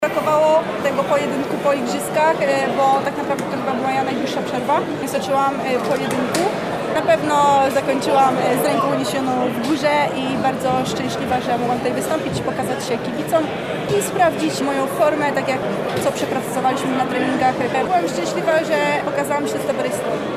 – Mówiła po walce Julia Szeremeta